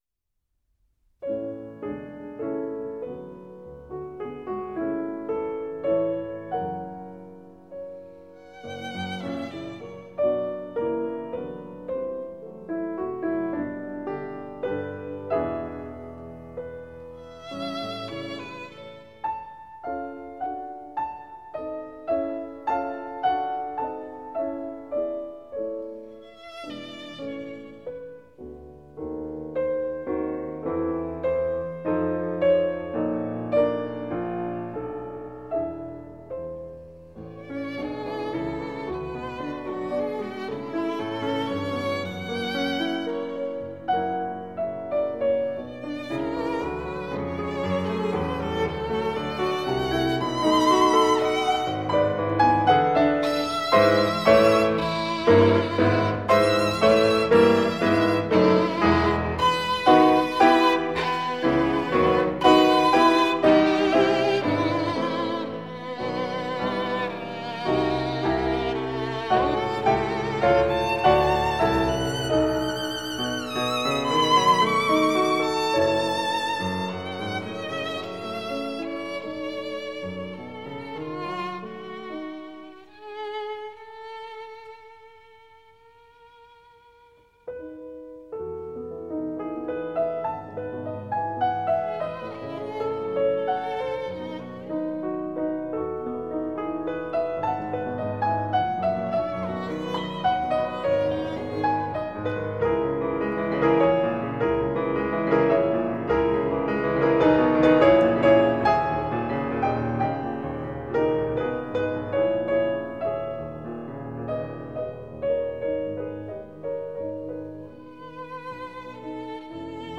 Sonata for Piano and Violin No 2 in A Major
Allegro amabile